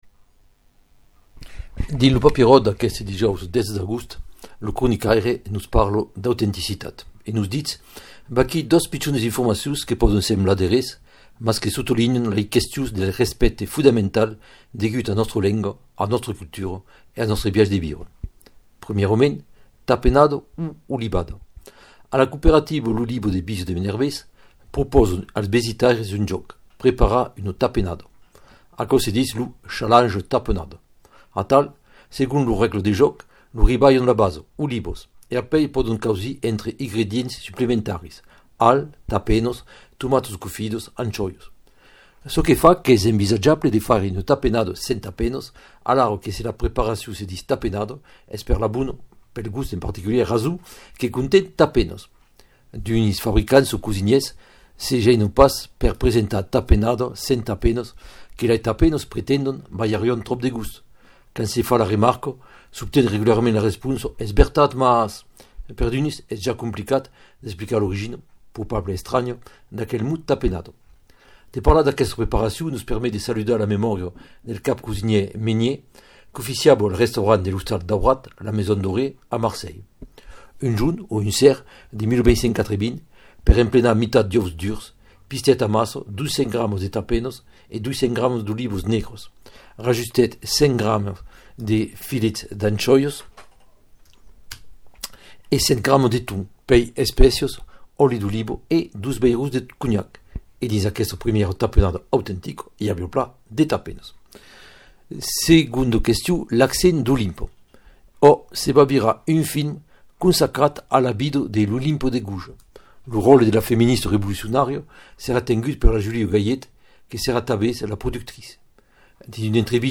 Lo Papieròt del dijòus sus ràdio Lengadòc